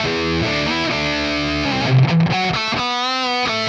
AM_RawkGuitar_130-E.wav